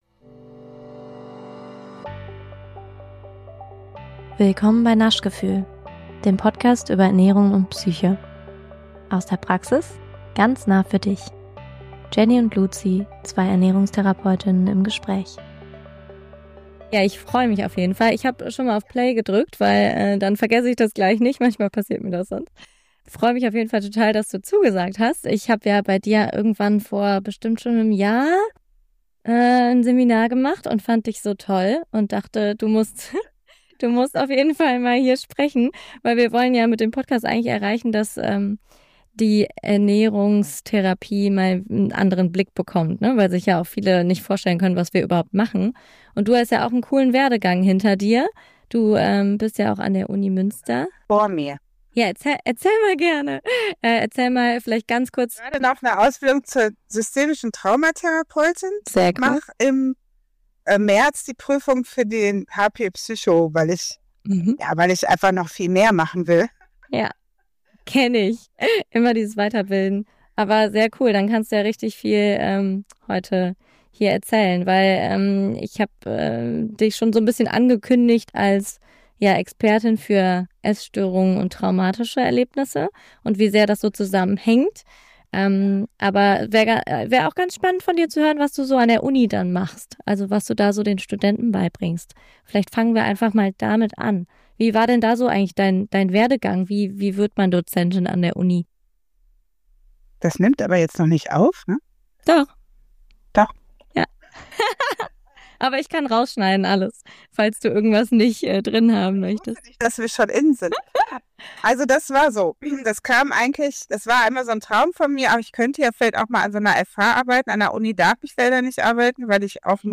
Der Ton ist hier und da nicht perfekt, bitte entschuldigt.